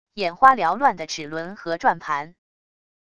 眼花缭乱的齿轮和转盘wav音频